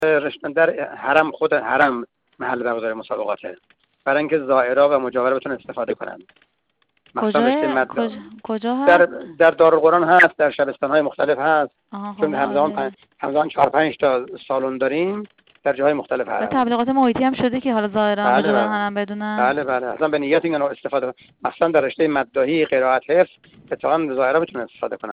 میکائیل باقری، مدیرکل قرآن، عترت و نماز وزارت آموزش و پرورش در گفت‌وگو با ایکنا ضمن بیان این مطلب گفت: مرحله نهایی چهل و یکمین دوره مسابقات قرآن، عترت و نماز دانش‌آموزان برگزیده سراسر کشور از فردا، 15 مرداد در بخش آوایی در مشهد مقدس آغاز می‌شود.